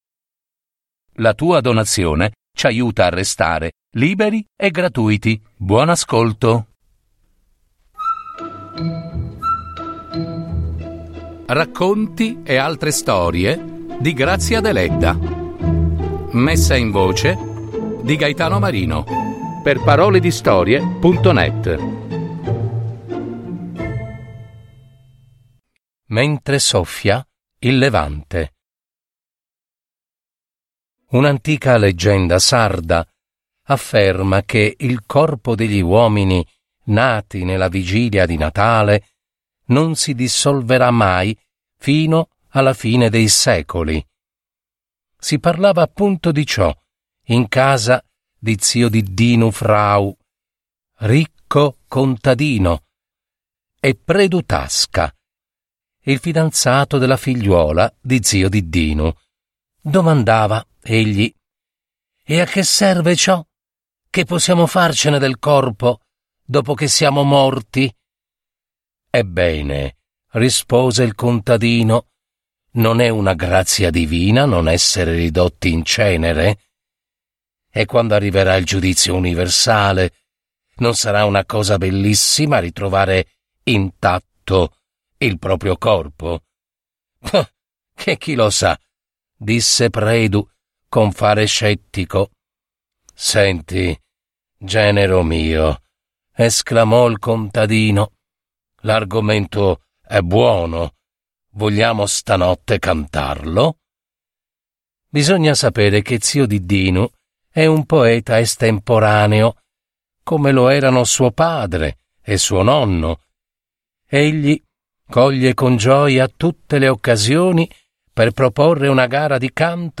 Messa in voce